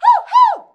HUH-HUH.wav